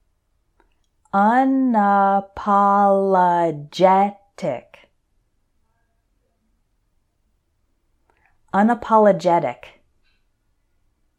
So for these words I’ll say them once slowly and once normally, so you can repeat both times.
un – a – po – lo – GET – ic……. unapologetic